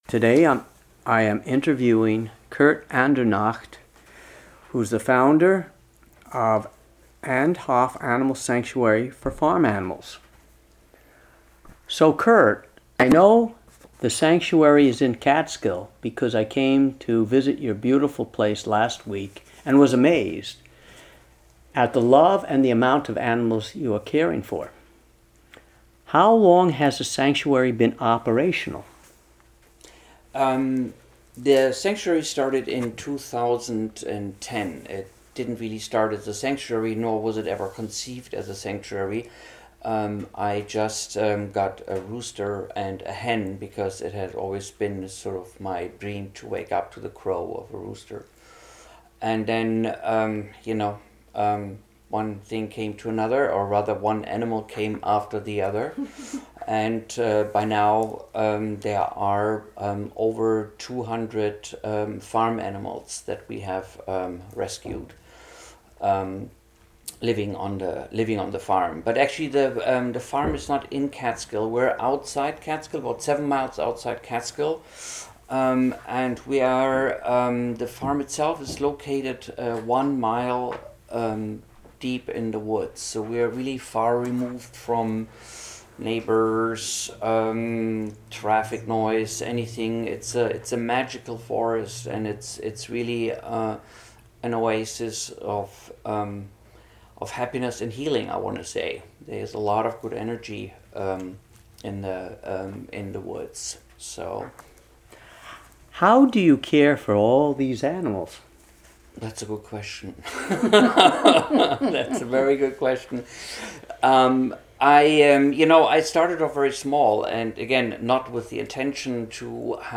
WGXC Afternoon Show